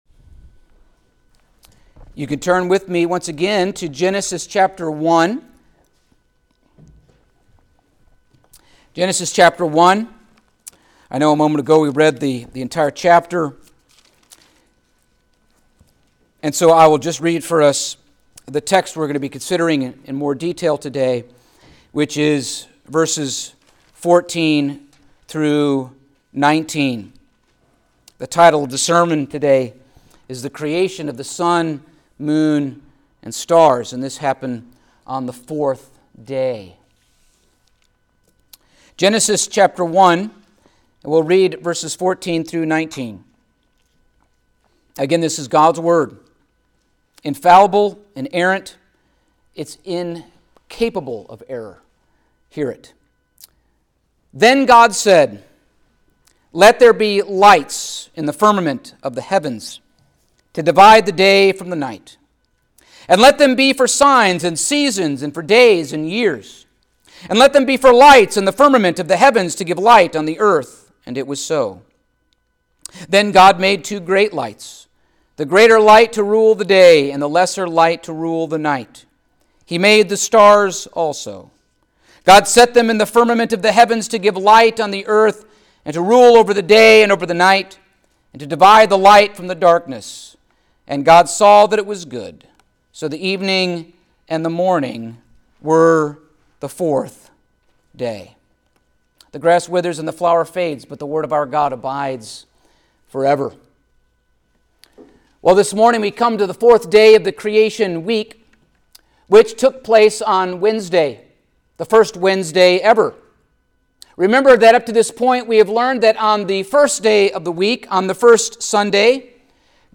Passage: Genesis 1:14-19 Service Type: Sunday Morning